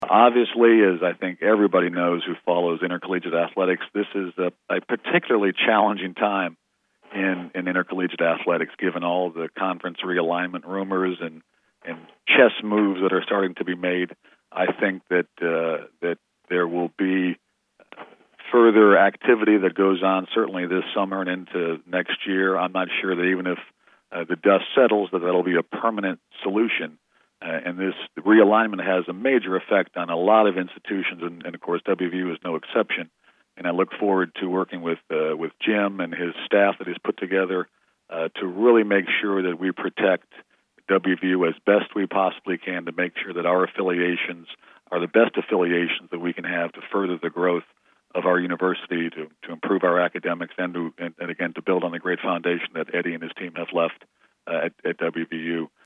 Click below to hear Oliver Luck talk about conference realignment: